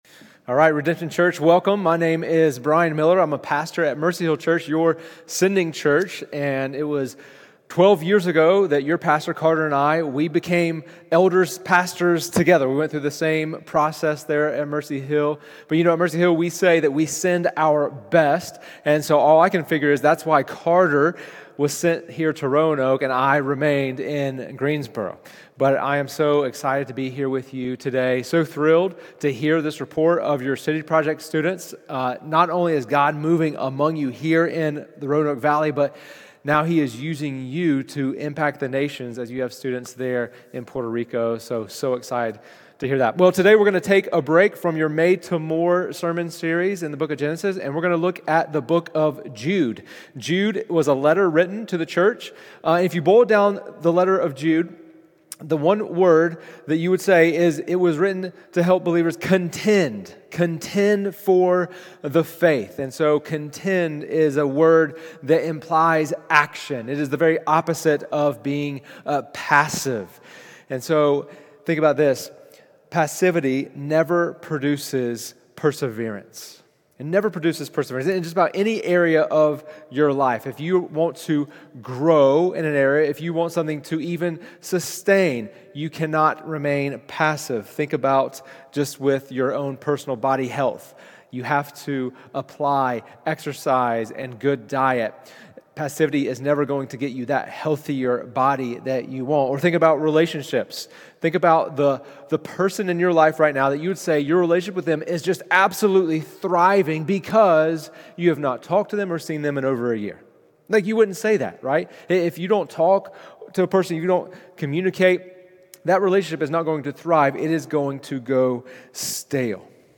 Today, we heard from guest speaker